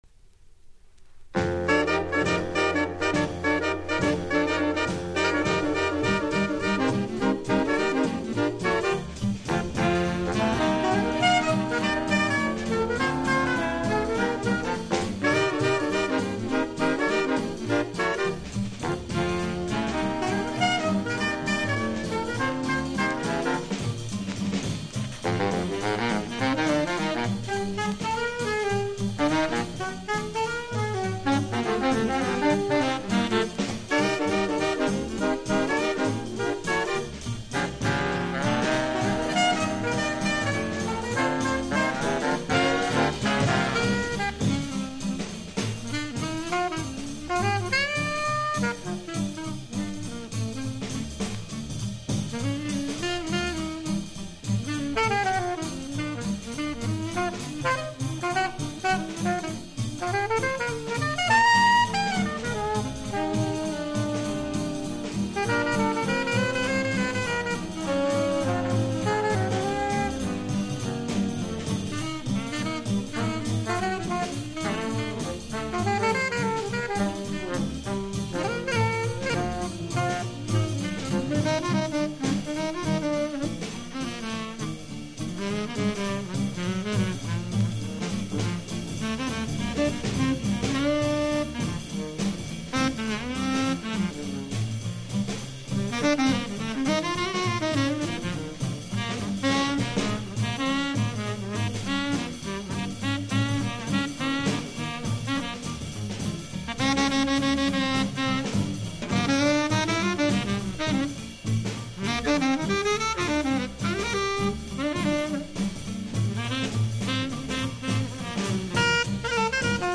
（プレスによりチリ、プチ音ある曲あり）
Genre US JAZZ